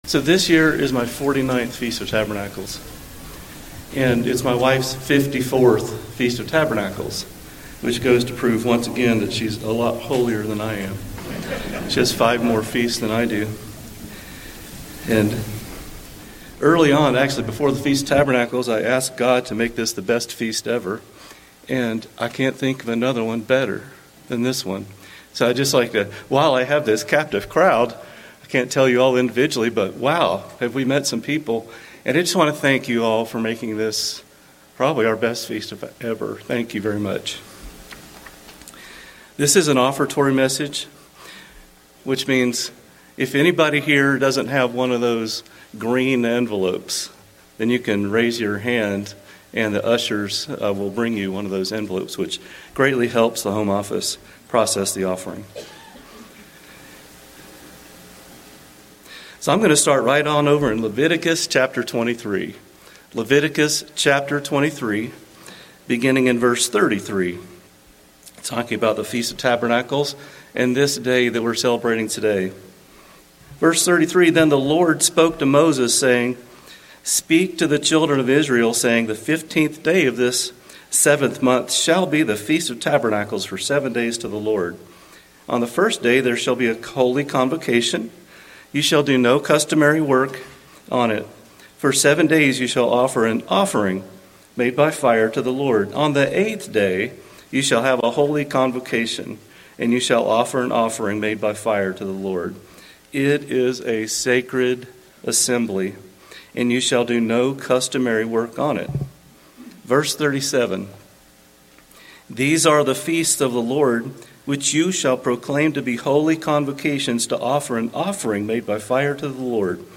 Given in Rapid City, South Dakota